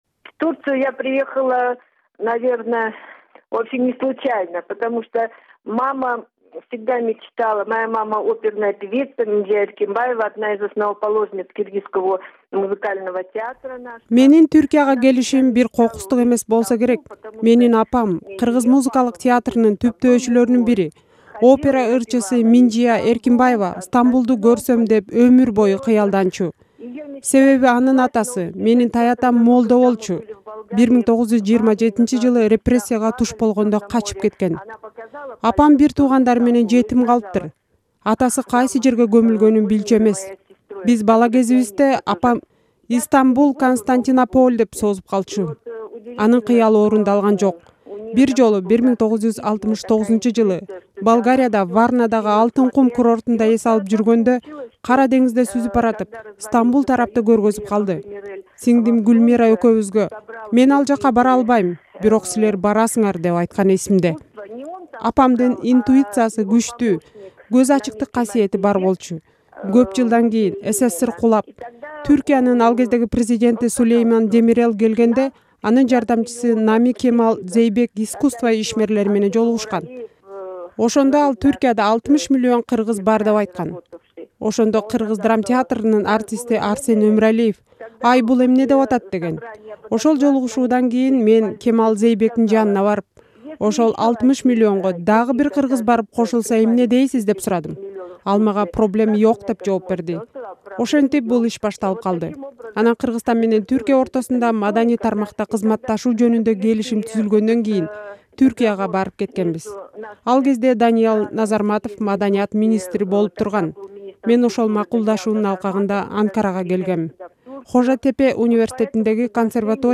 Interview with Aisuluu Tokombaeva, The Prominent Ballet Dancer, USSR People's Artist, Living in Turkey (АА)